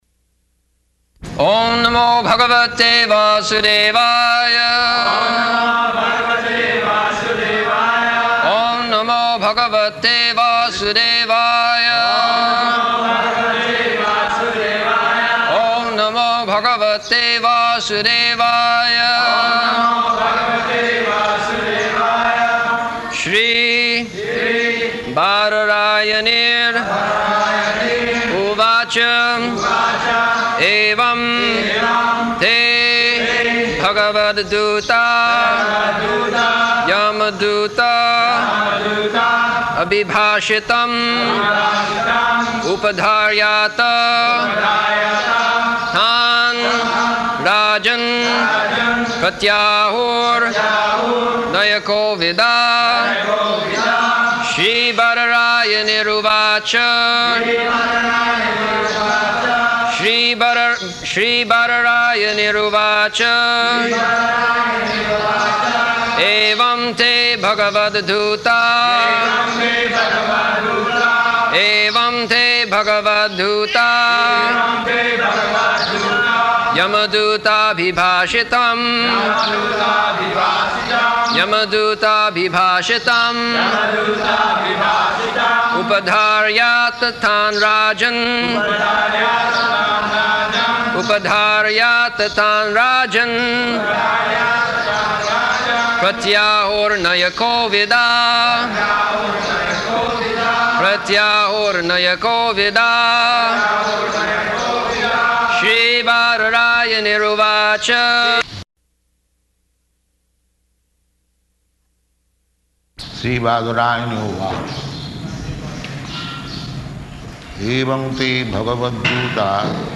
-- Type: Srimad-Bhagavatam Dated: September 5th 1975 Location: Vṛndāvana Audio file
[devotees repeat]